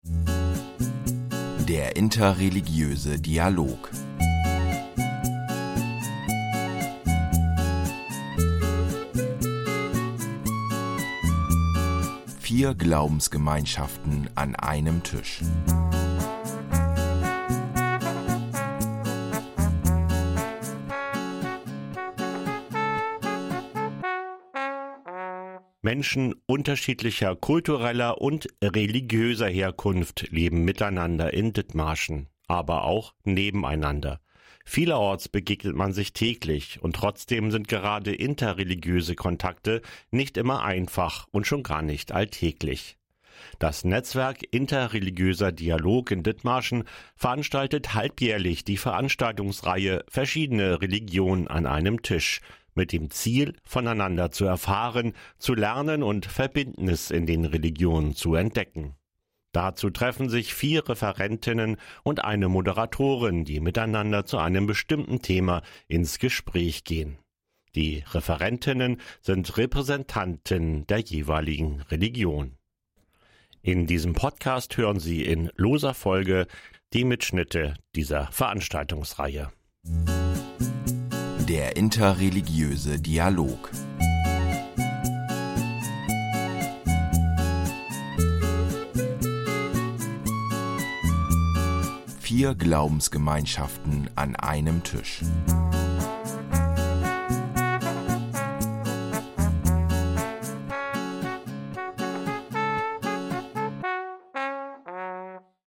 In diesem Podcast erscheinen in lockerer Folge die Mitschnitte